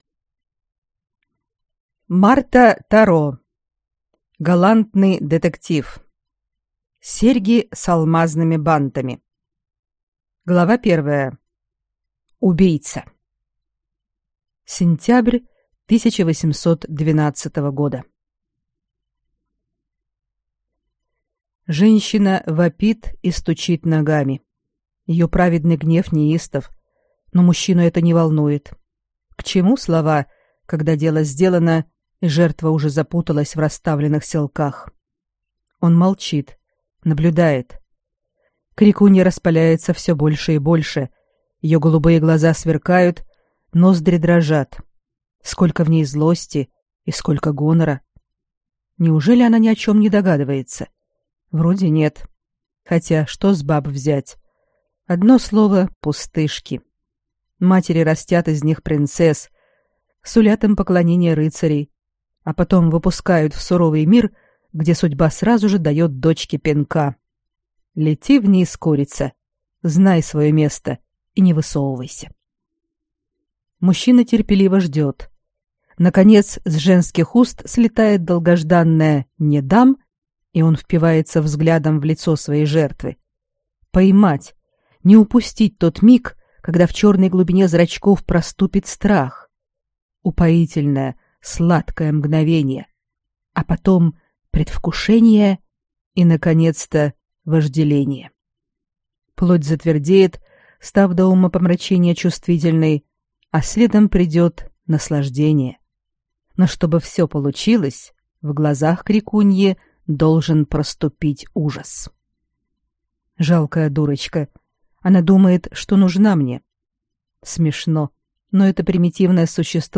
Аудиокнига Серьги с алмазными бантами | Библиотека аудиокниг